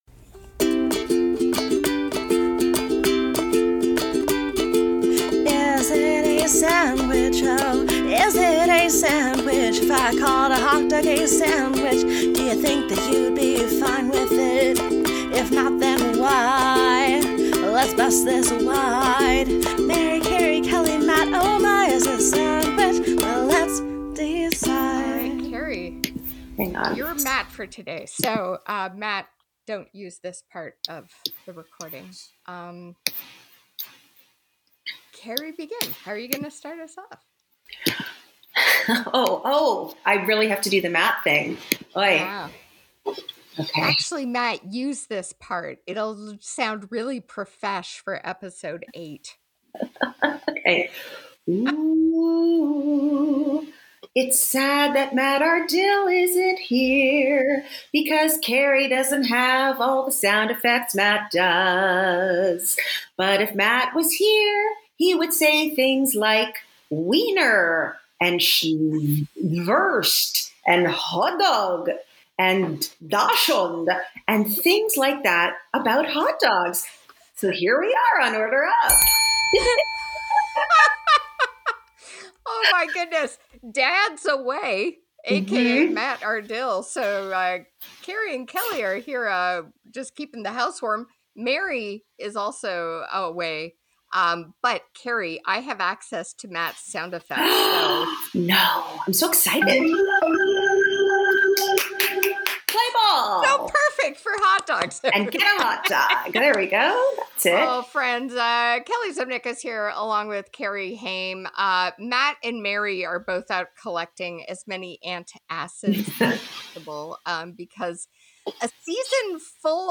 A fun conversation
four friends who love to talk food.